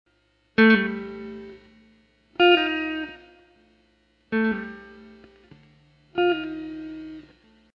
The first pull-off is played on the 4th string from the 7th fret to the 5th fret.
The second pull-off is played on the 2nd string from the 6th fret to the 4th fret.
The two pull-off's notated above sound like this: